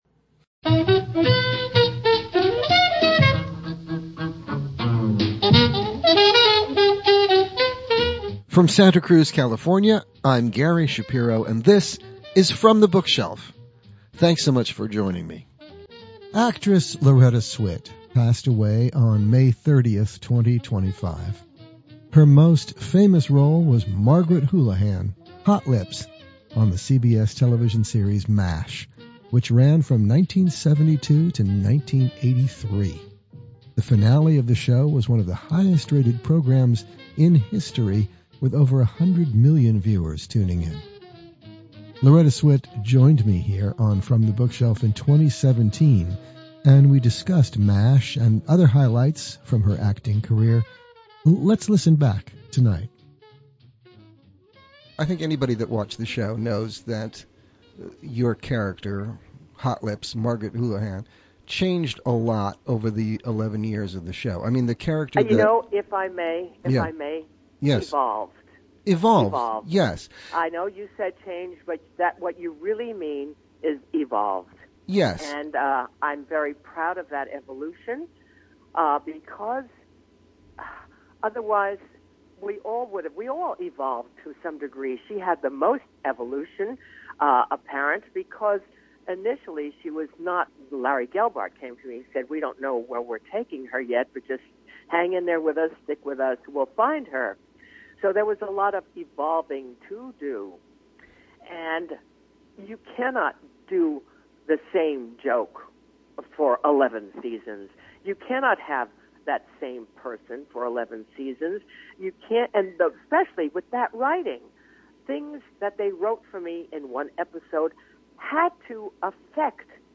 From the Bookshelf is heard on radio station KSQD in Santa Cruz California.